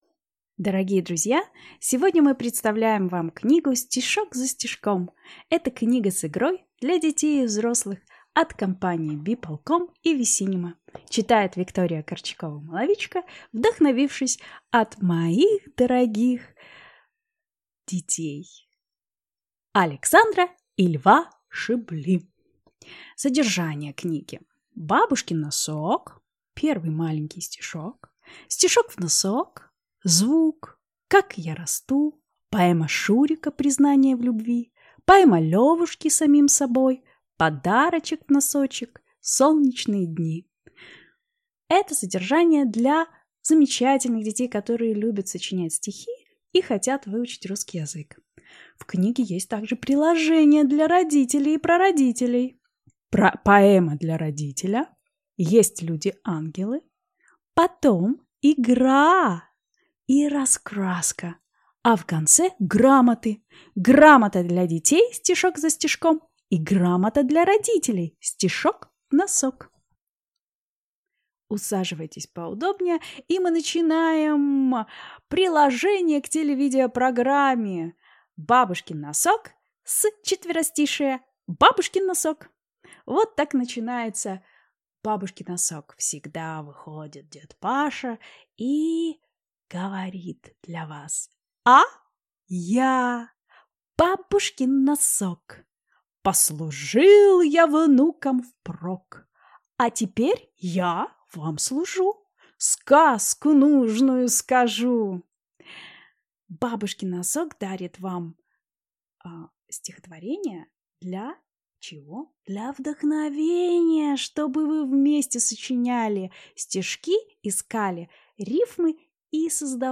Аудиокнига Стишок за стишком | Библиотека аудиокниг